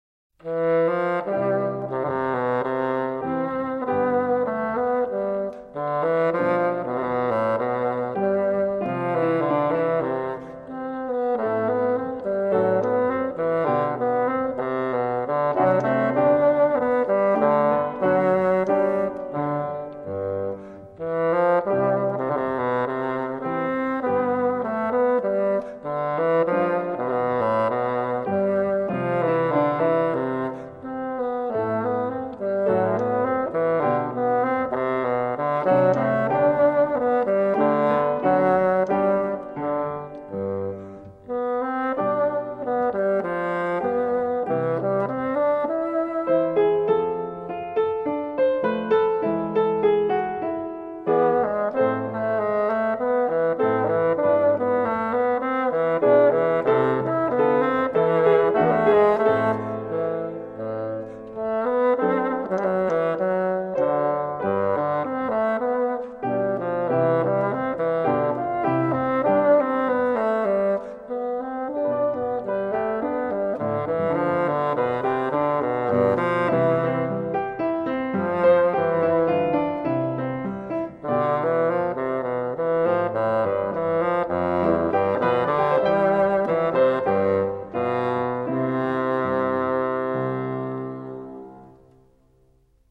癒しの音楽